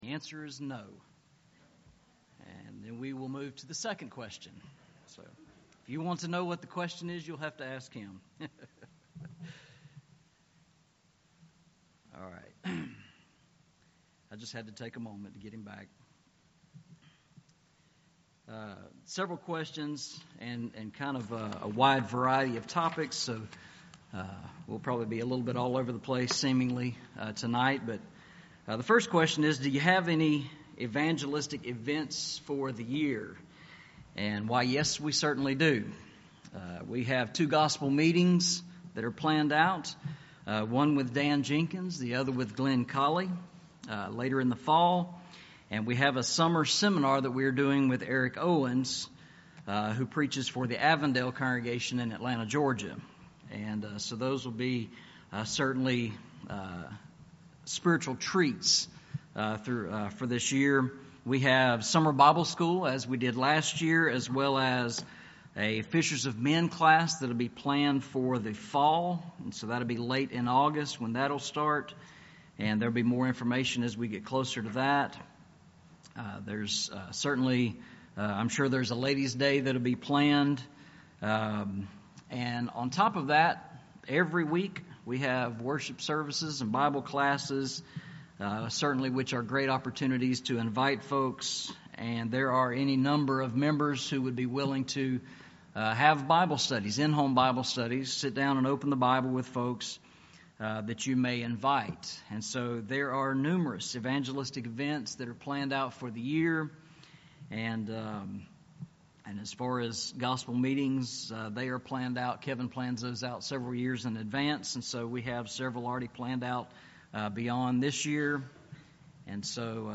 Eastside Sermons Passage: 1 Peter 1:15-16 Service Type: Sunday Morning « Walking Through the Bible